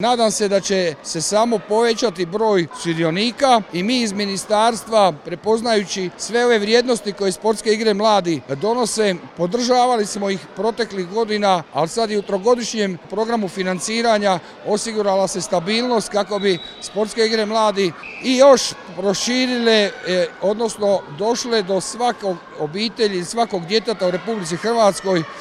ZAGREB - Mladi sportaši i ljubitelji sporta okupili su se na terenima Nogometnog centra Šalata u Zagrebu gdje je službeno započela ovogodišnja sezona Sportskih igara mladih, malonogometnim turnirom i sportsko-ekološkim projektom Zero Waste.
Na svečanosti otvorenja okupljene su pozdravili, među ostalim i načelnik Sektora za razvoj i natjecateljski sport pri Ministarstvu turizma i sporta Darko Vučić: